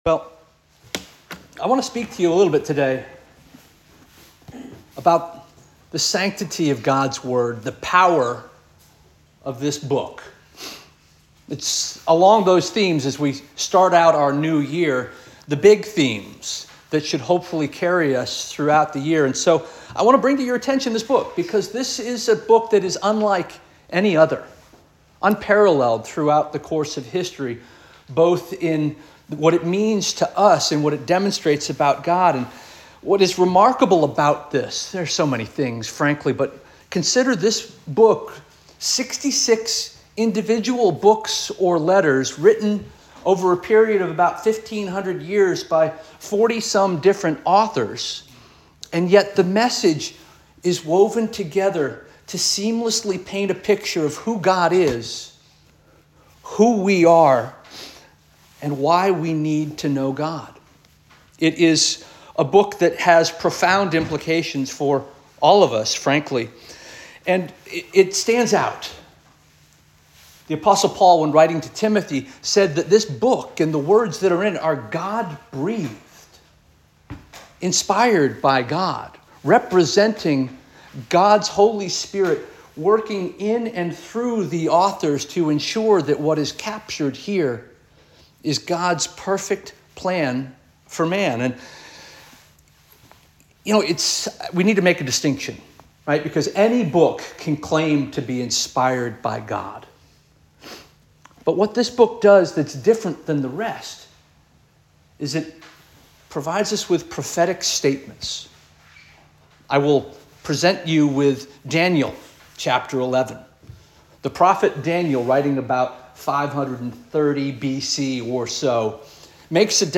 January 19 2025 Sermon - First Union African Baptist Church